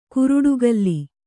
♪ kuruḍu galli